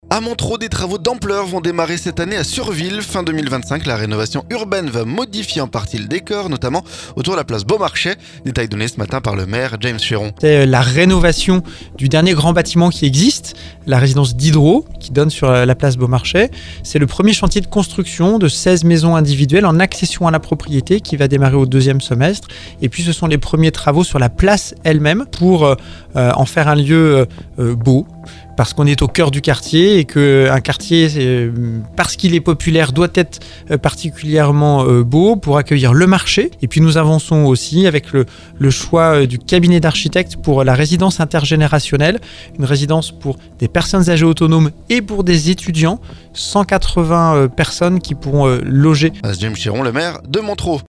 Notamment autour de la place Beaumarchais. Détails donnés ce matin par le maire James Chéron.